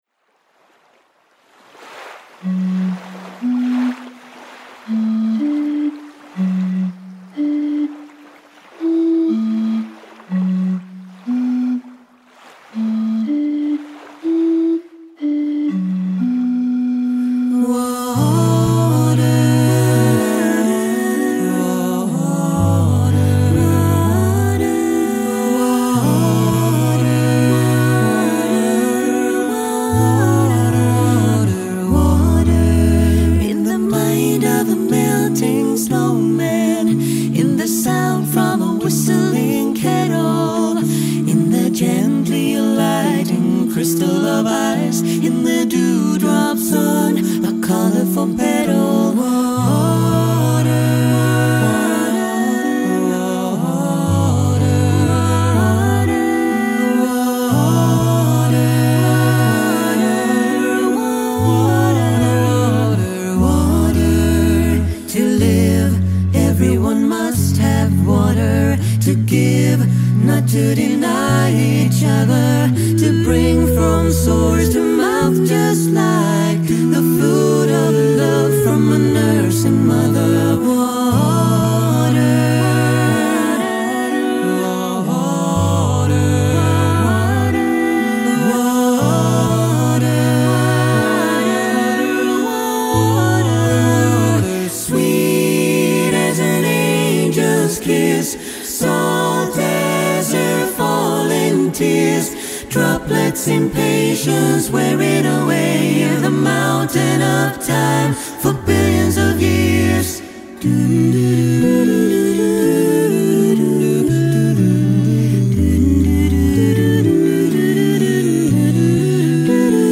Voicing: SATBB/btls